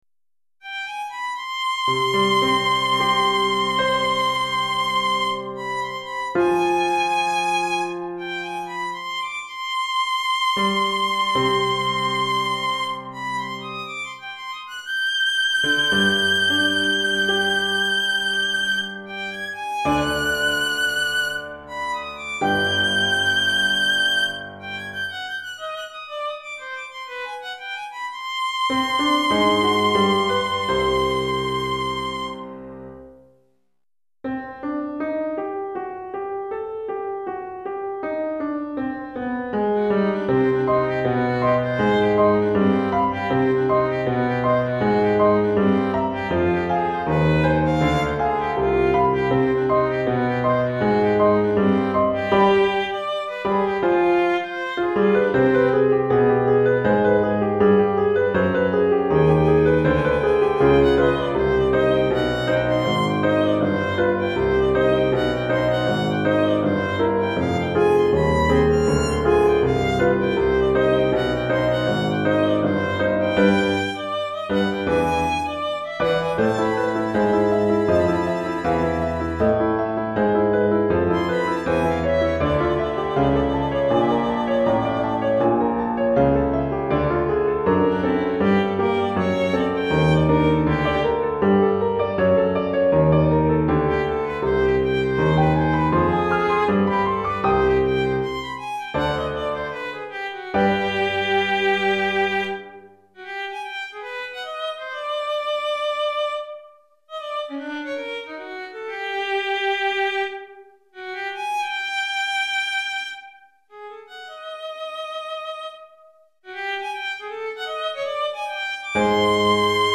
Violon et Piano